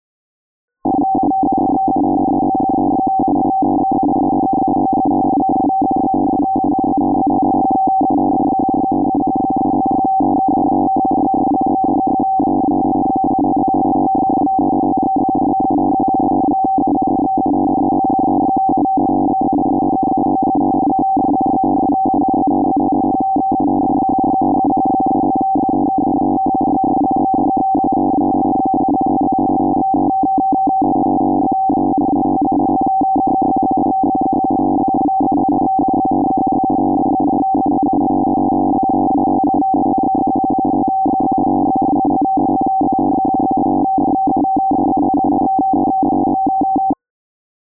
FSK-2, shift 480 Hz, speed 96 Hz
Ширина спектра (Band Width) ~670 Hz
Частота манипуляции (Baud Rate) ~96 Hz
Модуляция, в которой сигнал принят (RX mode) SSB
CIS-14 - Отечественный FSK-2 сигнал, из особенностей только скорость ~ 96 Гц и длинна фрейма 14 бит, хорошо видно на рис.4 не совсем стабильную скорость манипуляции, причин может быть много, как на передающей стороне, так и на приемной.